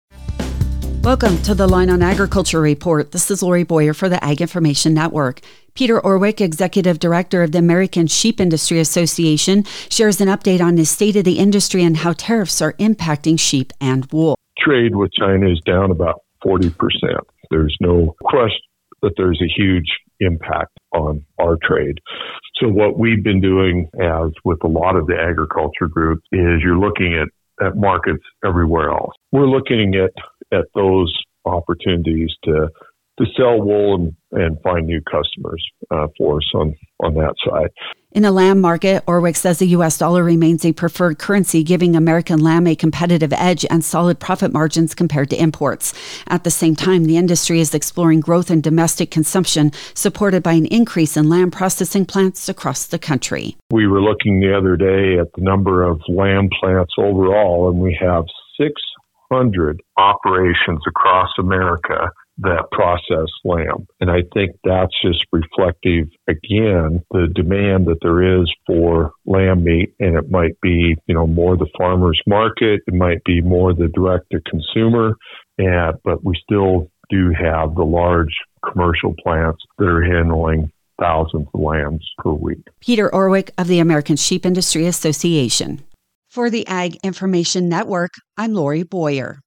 Reporter